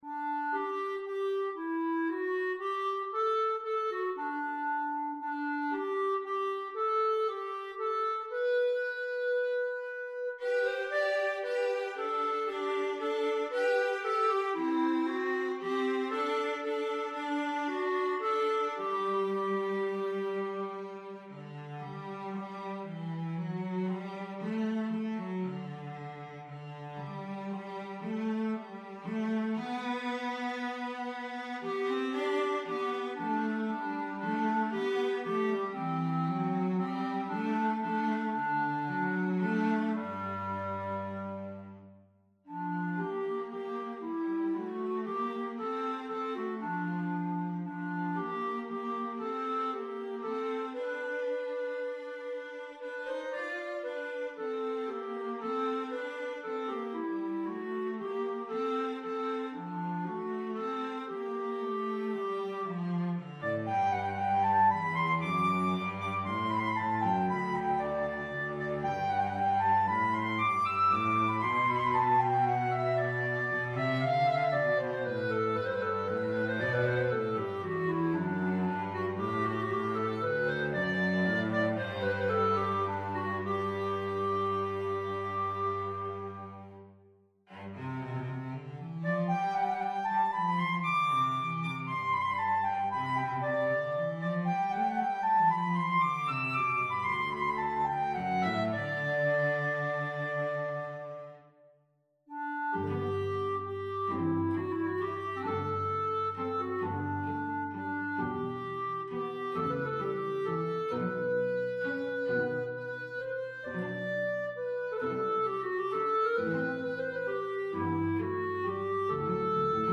for clarinet or violin and cello